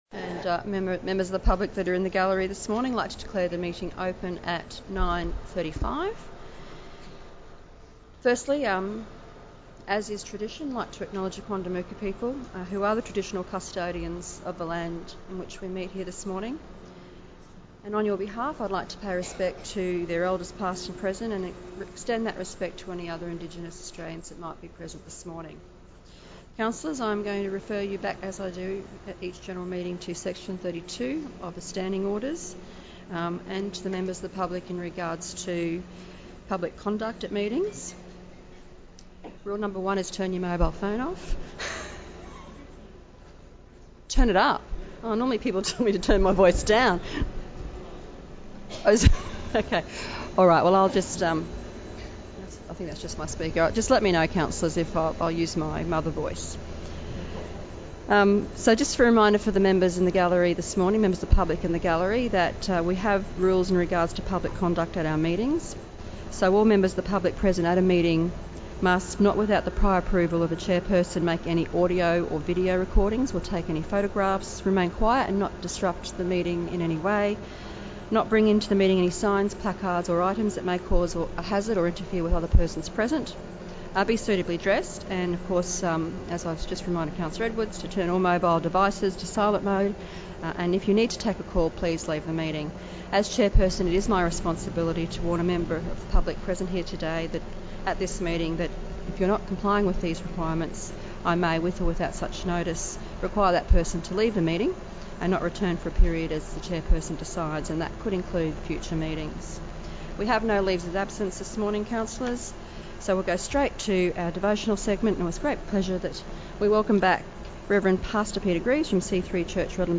25 July 2018 - General meeting